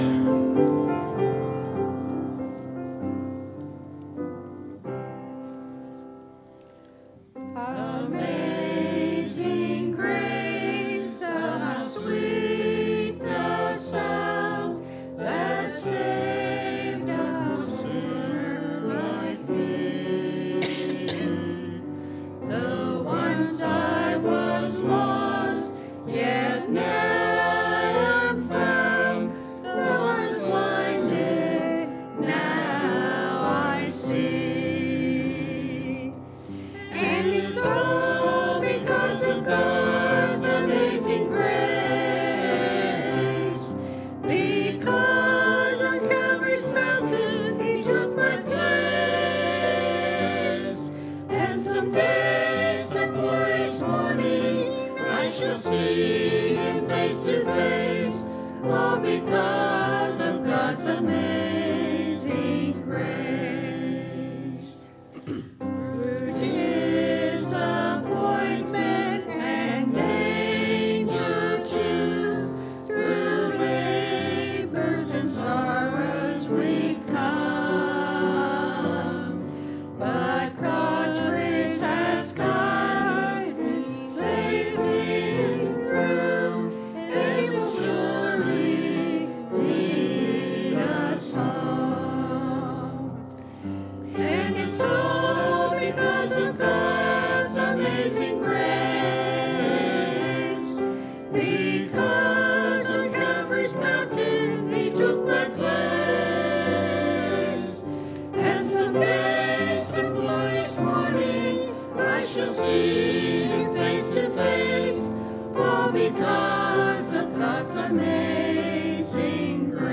2 Share this sermon